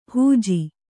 ♪ hūji